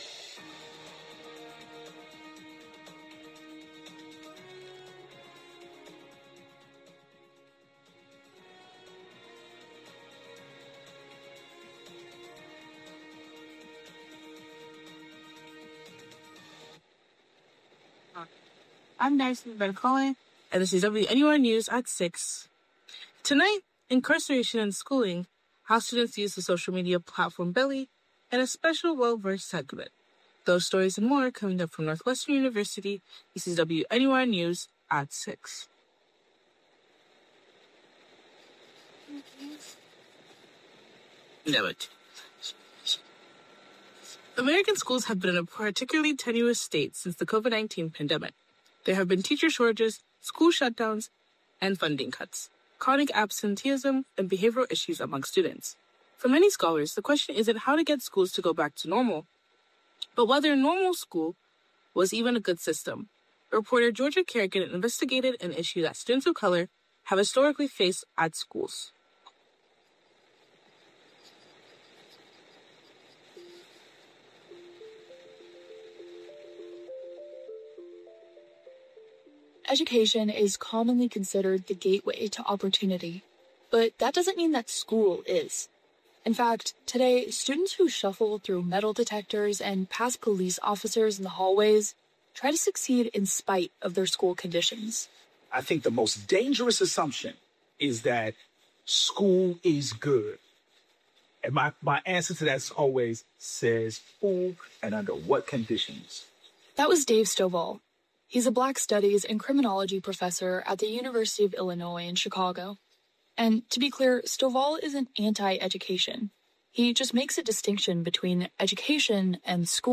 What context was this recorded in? March 4, 2026: Incarceration and schooling, Beli: a popular food social-media, Well-versed. WNUR News broadcasts live at 6 pm CST on Mondays, Wednesdays, and Fridays on WNUR 89.3 FM.